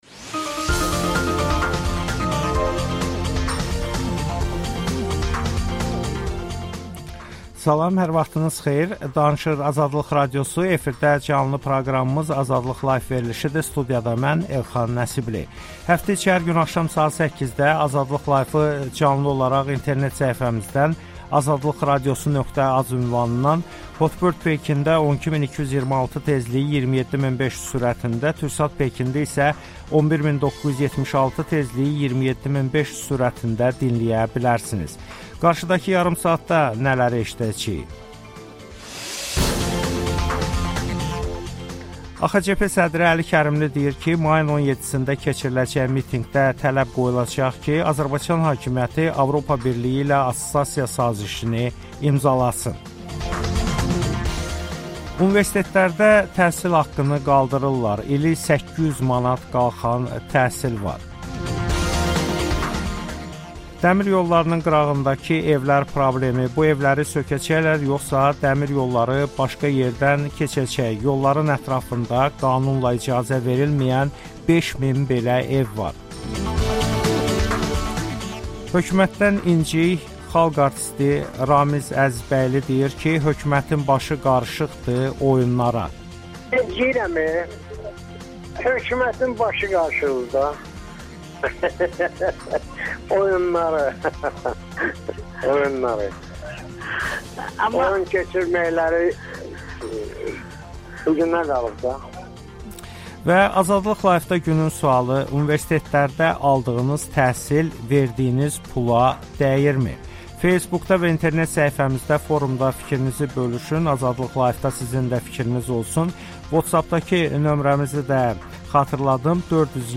müsahib, Universitetlərdə təhsil haqqı artırılır